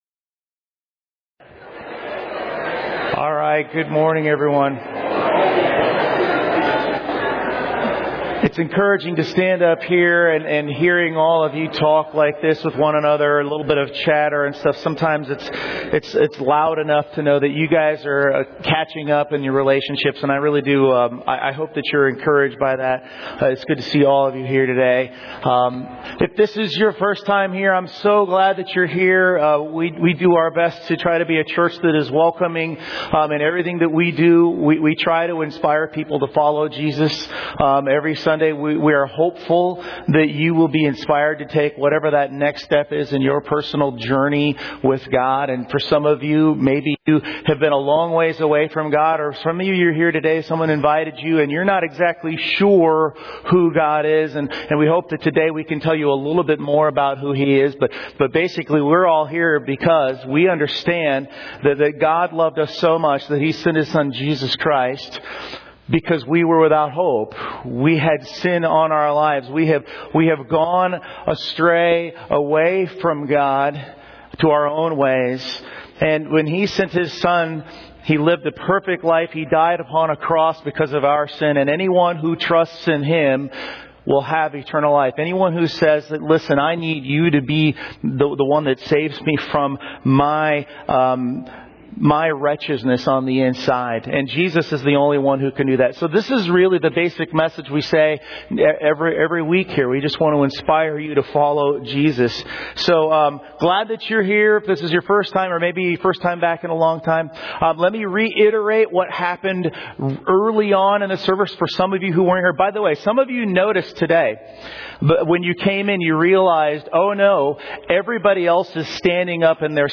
Series: 2024 Sermons